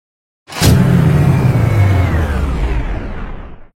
Jojo unofficial Mandom sfx
jojo-unofficial-mandom-sfx.mp3